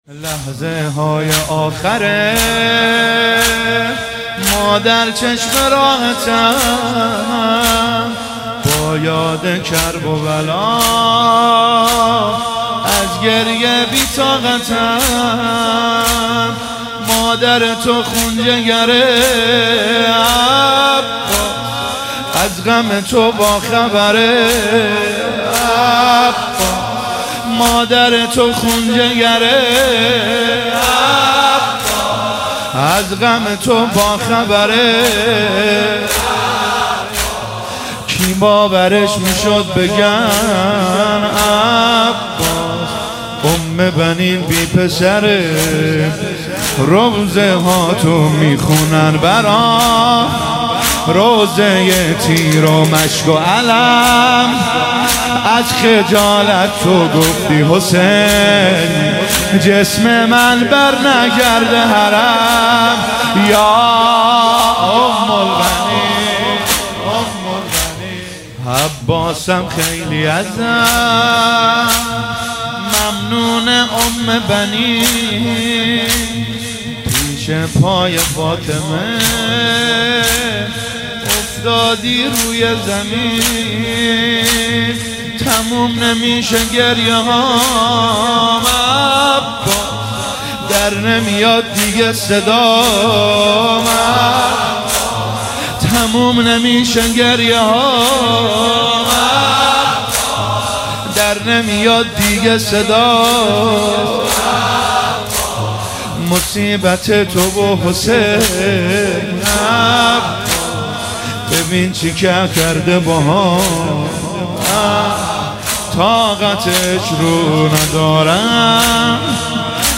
وفات حضرت ام البنین (س)